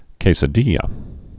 (kāsə-dēyə)